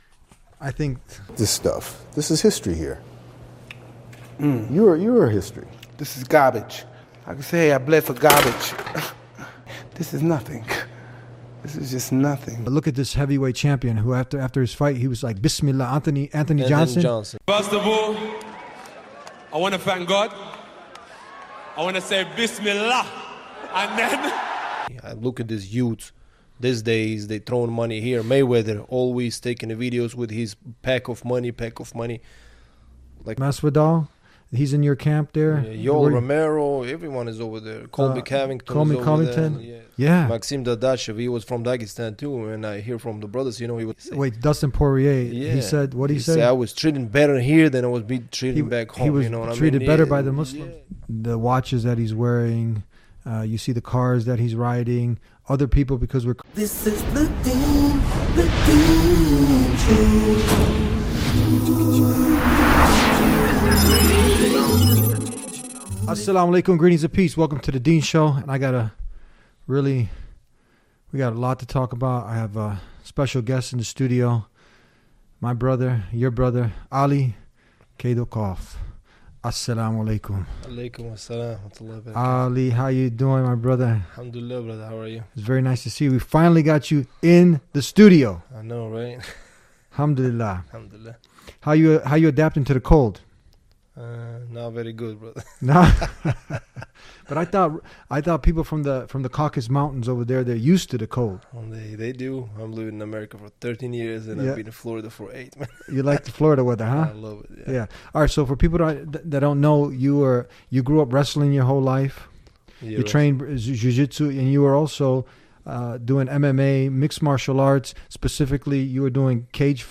a former MMA fighter